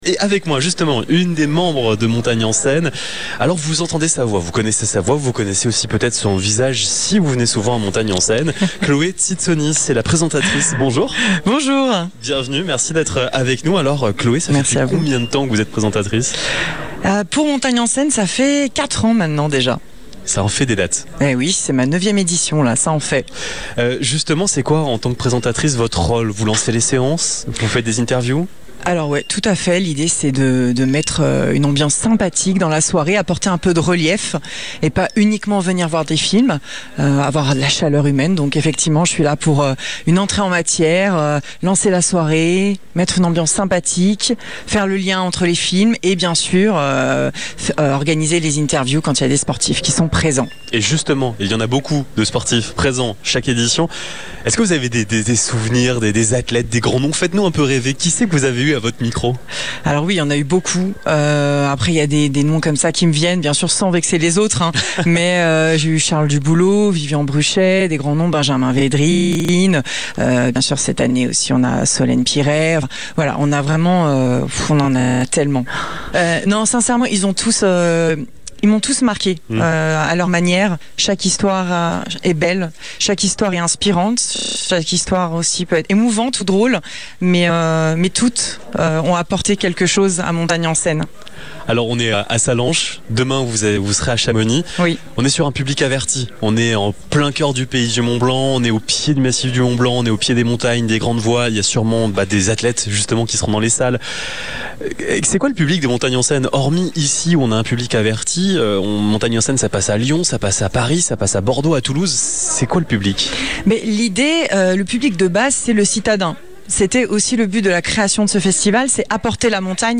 Ce mercredi 26 novembre, nous avons posé nos micros au Ciné Mont-Blanc, le multiplex emblématique de la vallée, à l'occasion du Festival Montagne en Scène.
Interview